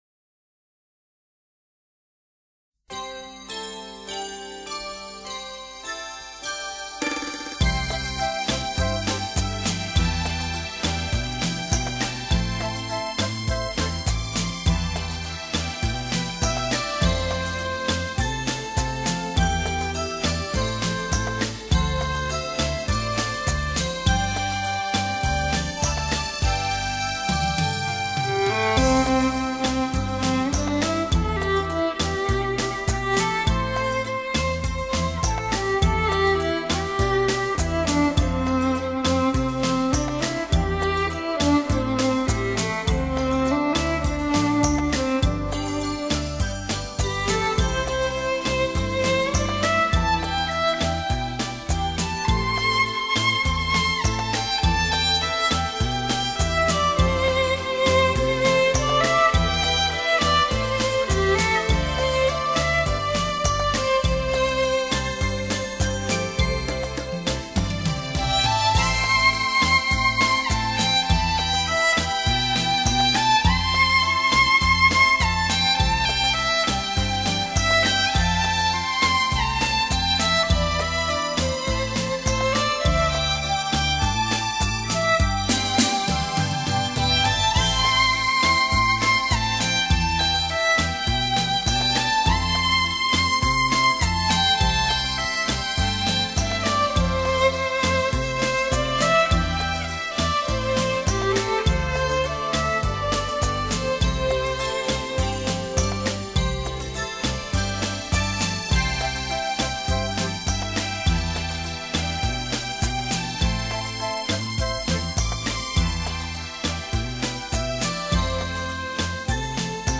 浪漫的萨克斯，
轻柔的钢琴，
聆听清雅怡人的音乐，
深情不腻的旋律，